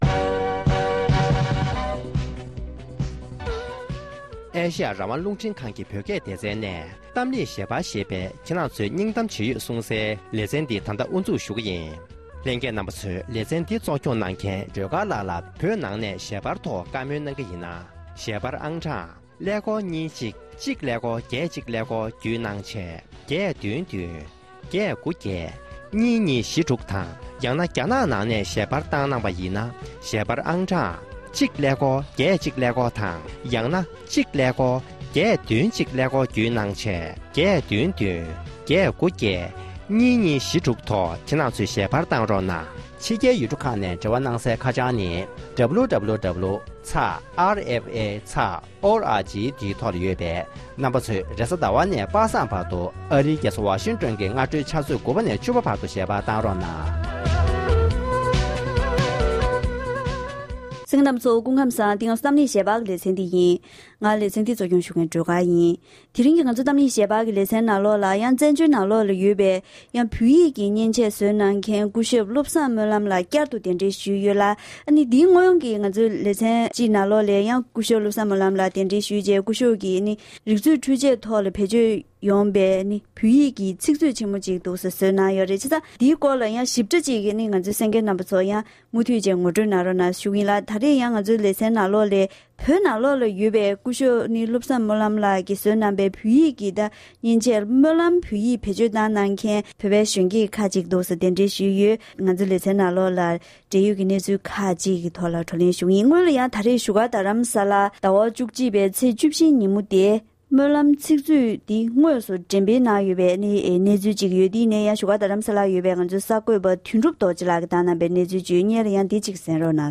དེ་རིང་གི་གཏམ་གླེང་ཞལ་པར་གྱི་ལེ་ཚན